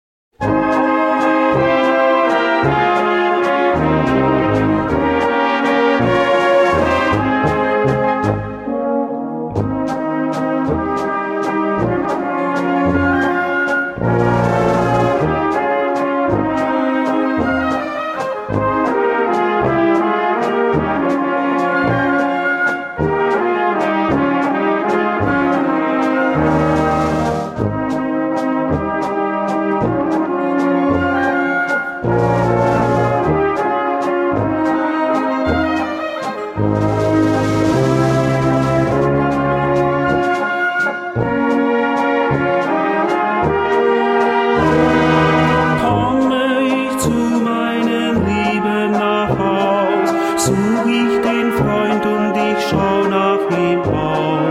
Gattung: Walzer
Besetzung: Blasorchester
Inkl. Text- bzw. Gesangsstimme.